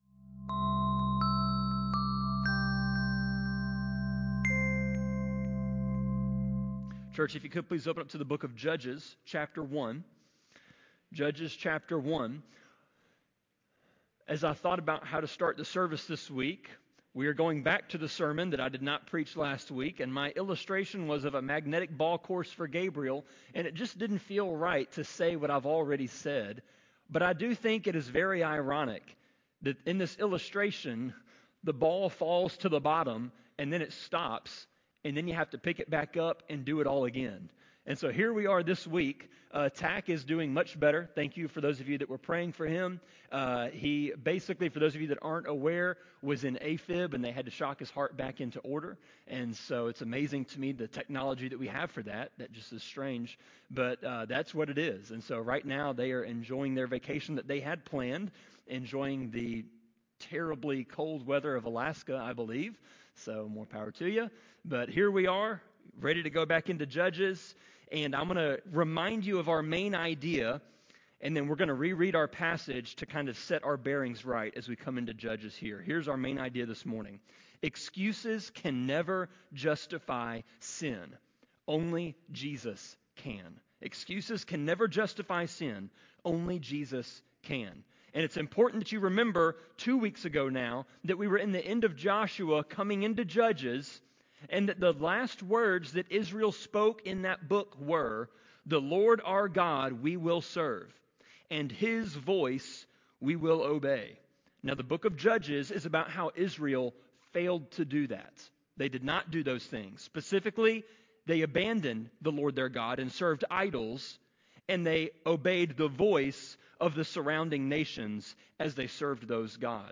Sermon-25.9.7-CD.mp3